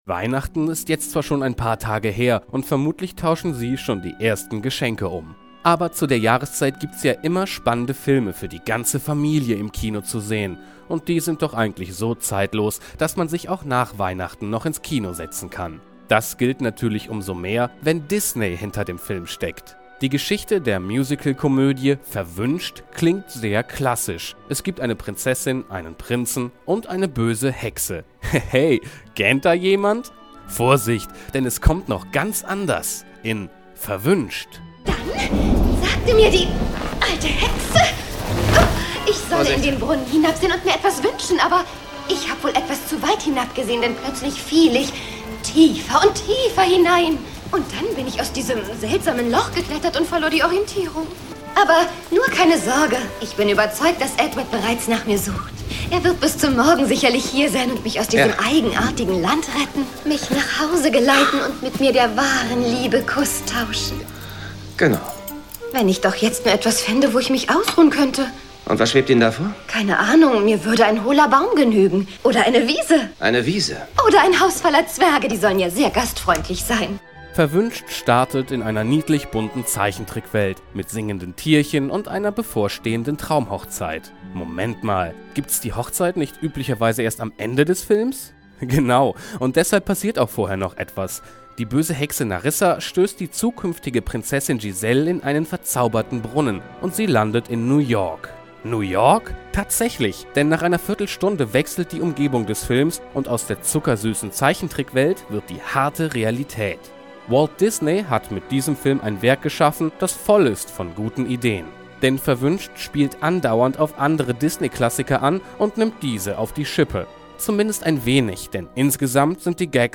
Kinotipp_Verwuenscht.mp3